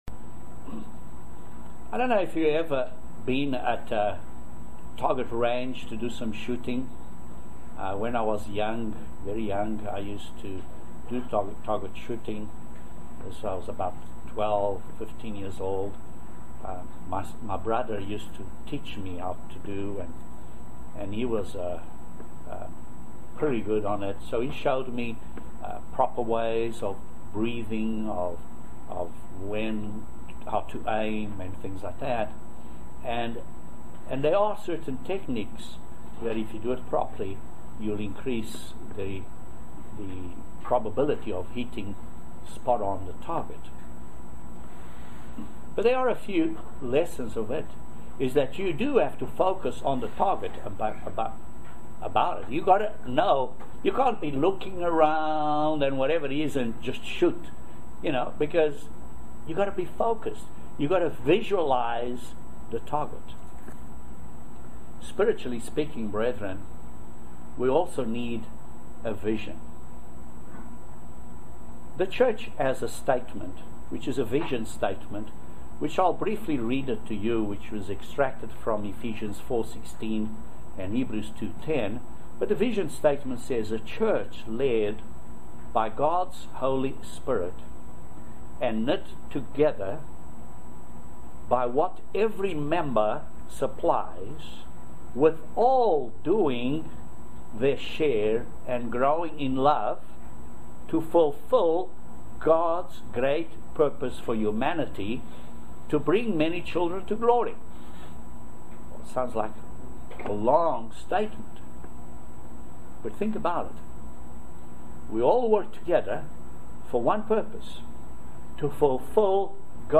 Please join us for this Amazing video sermon explaining some of the prophecies yet to be fulfilled in the old and new testaments. This is a very interesting study in the book of Daniel, and in Revelation.